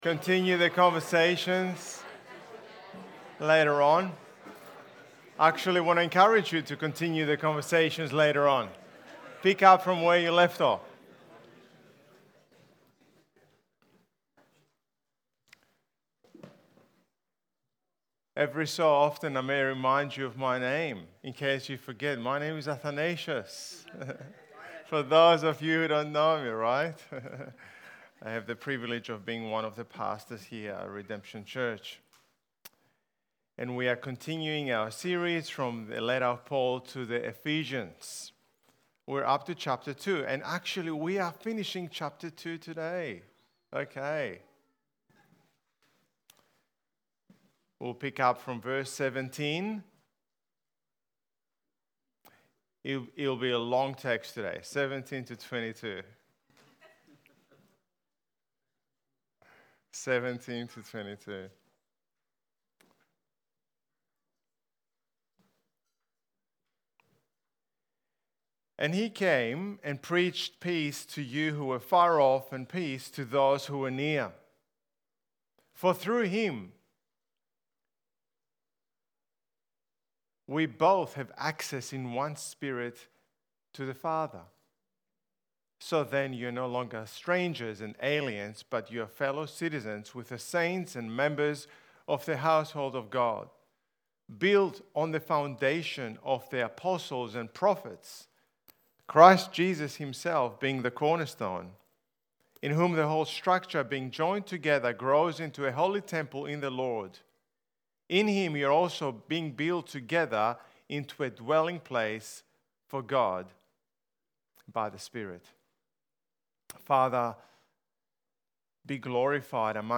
Sermon Series